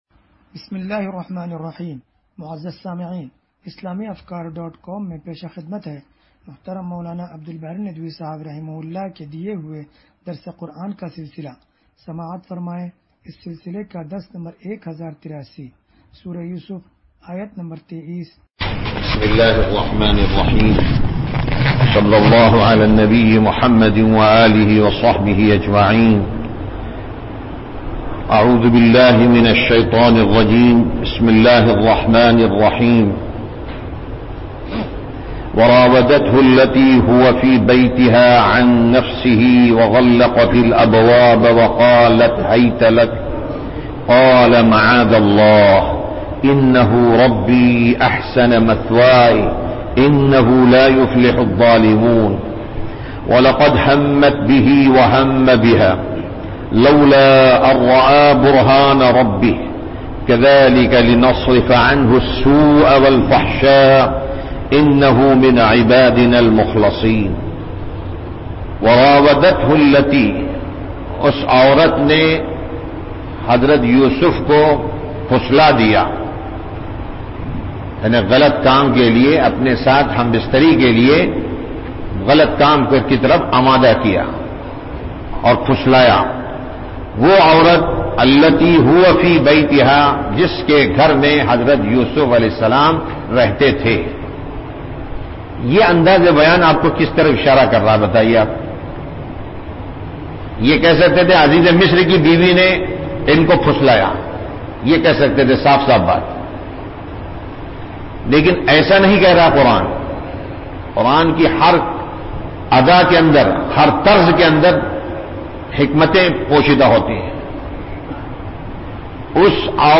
درس قرآن نمبر 1083